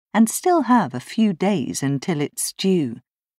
女性の台詞を続けて聞いていきましょう。
理由は、until it’s が「ンーテリッツ」 と発音されているからです。